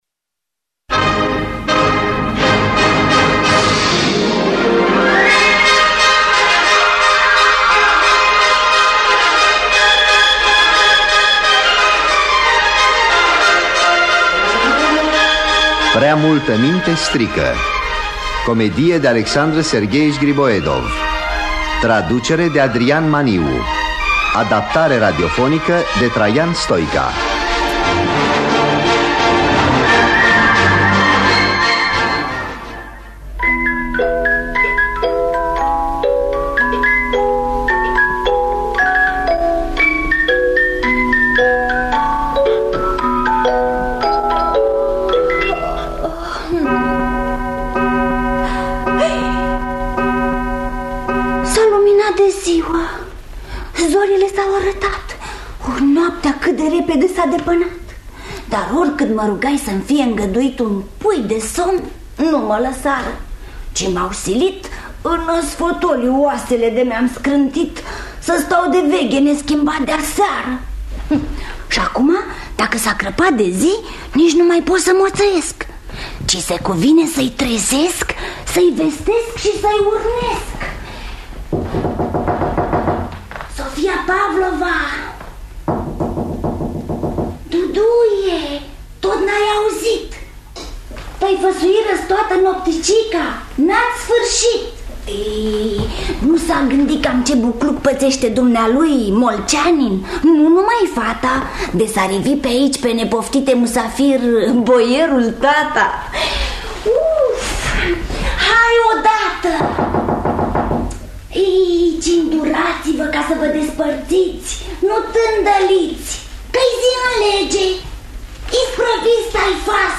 Prea multă minte, strică de Alexander Griboedov – Teatru Radiofonic Online